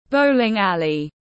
Trung tâm bâu-ling tiếng anh gọi là bowling alley, phiên âm tiếng anh đọc là /ˈbəʊ.lɪŋ ˌæl.i/.
Bowling alley /ˈbəʊ.lɪŋ ˌæl.i/